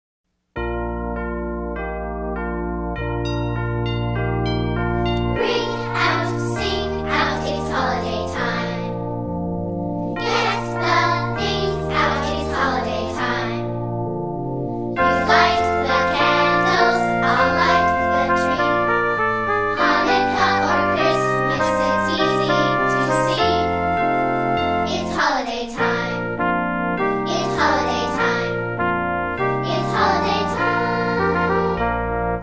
▪ The full-length music track with vocals.
Demo MP3